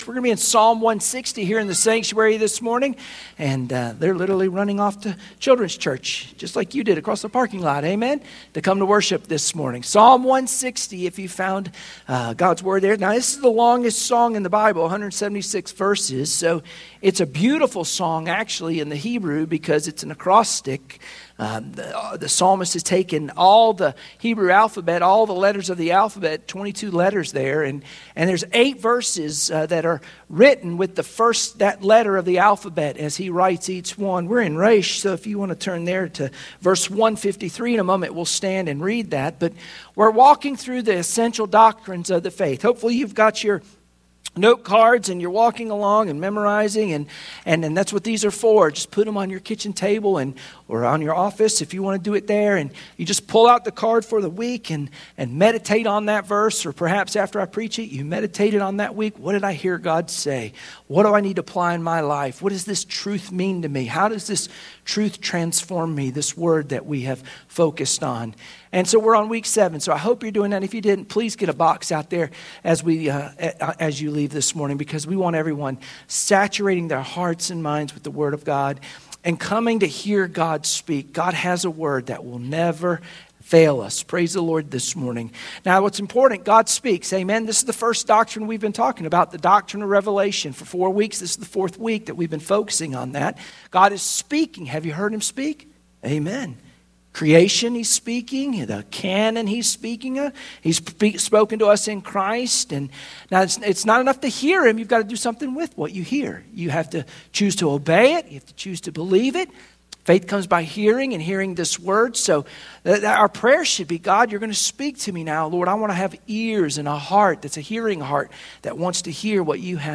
Sunday Morning Worship Passage: Psalm 119:153-160 Service Type: Sunday Morning Worship Share this